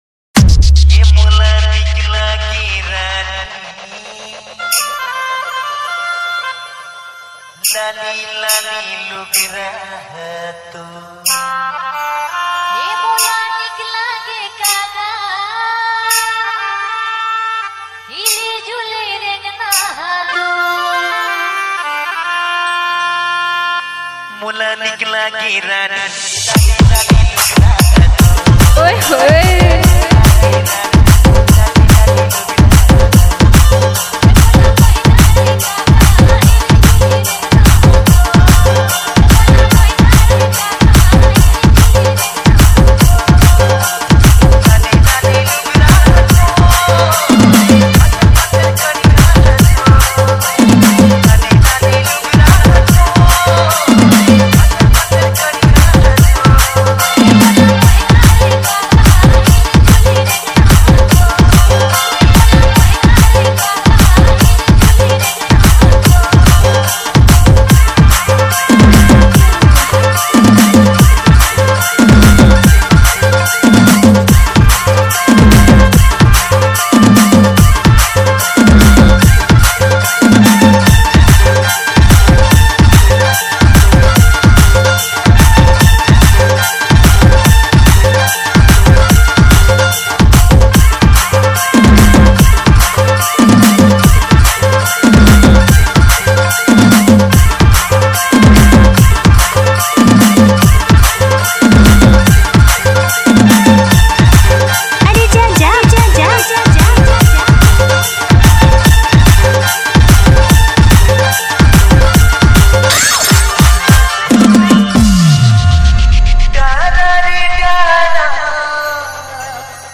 CG ROMANTIC DJ REMIX